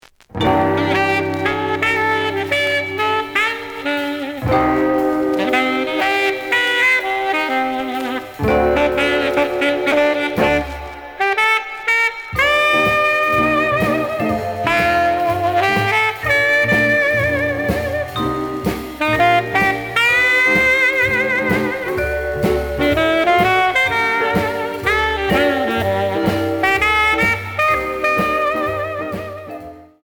●Genre: Rhythm And Blues / Rock 'n' Roll
Slight noise on beginning of B side, but almost good.)